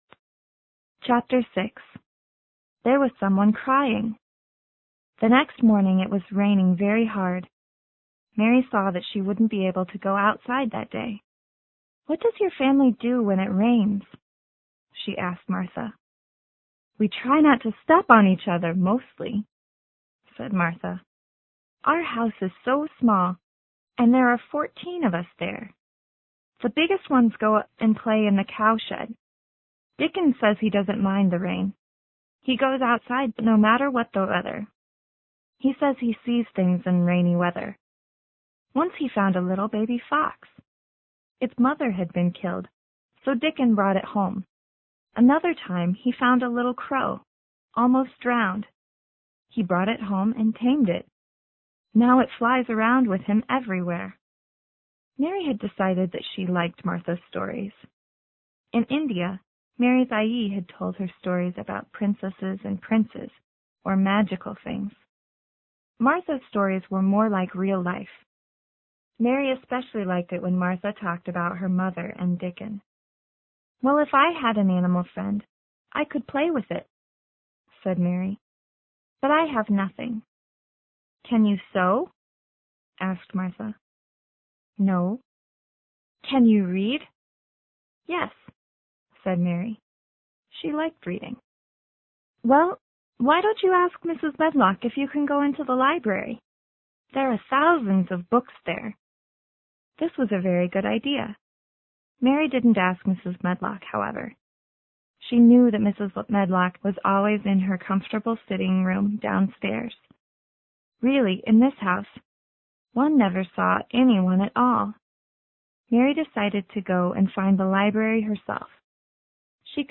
有声名著之秘密花园 Chapter6 听力文件下载—在线英语听力室